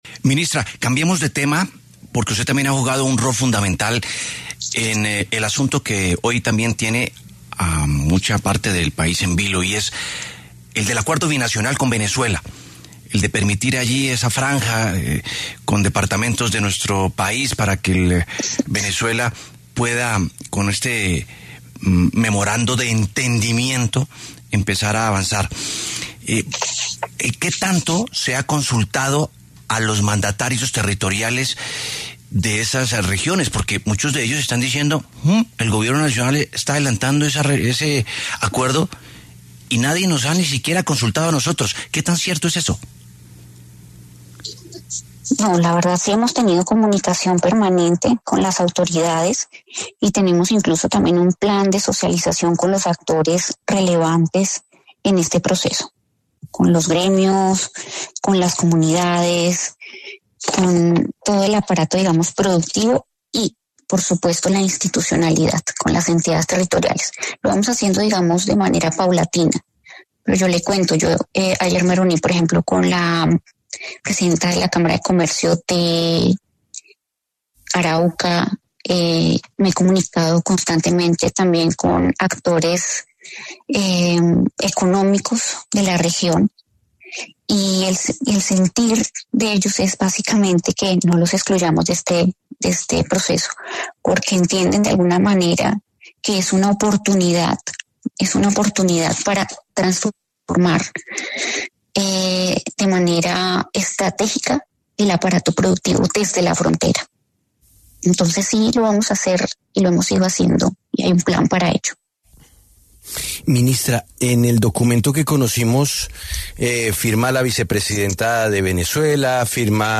Para hablar sobre el tema, pasó por los micrófonos de La W la ministra de Comercio, Diana Morales, quien, en representación del Gobierno Nacional, firmó dicho documento.